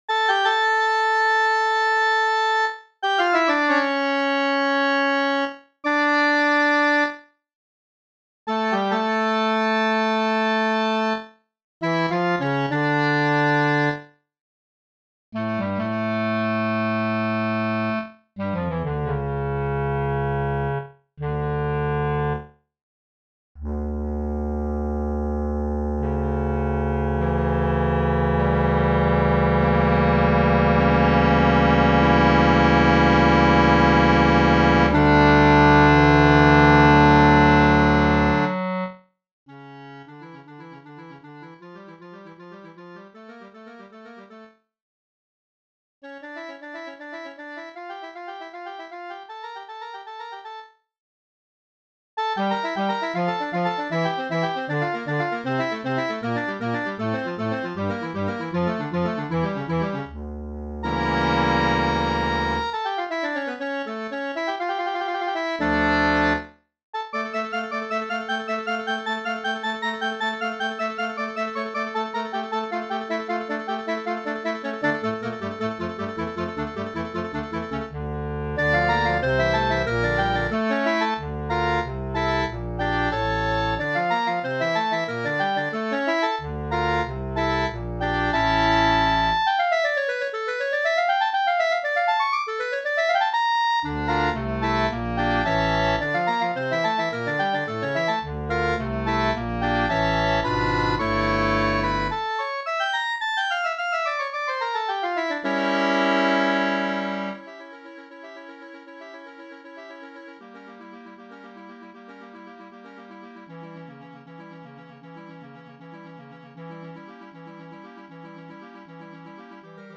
Once the fugue starts it is smoother sailing.